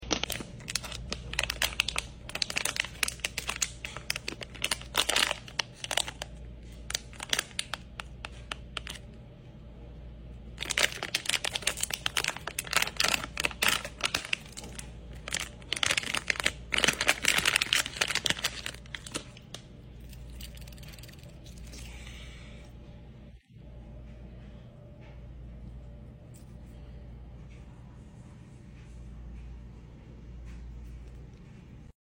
wnba figural bag clip asmr sound effects free download